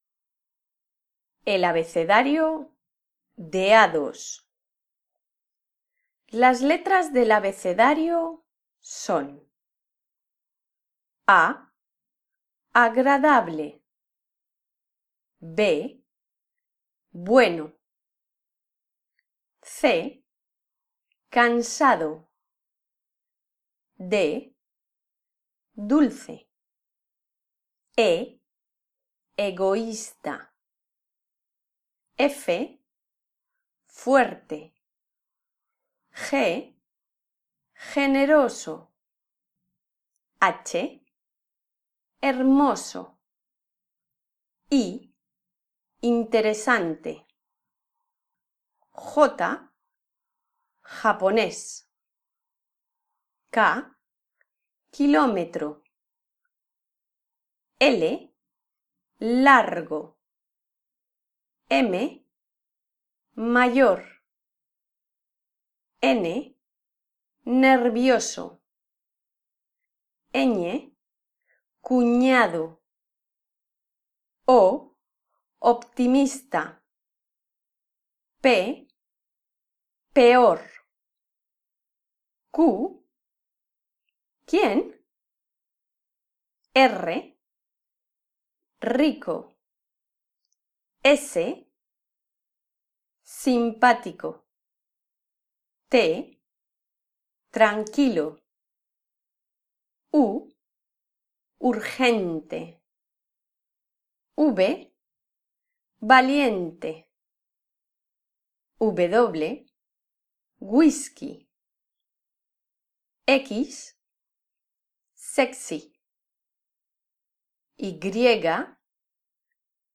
Un audio para practicar la pronunciación de las letras. Adjetivos físicos y de carácter.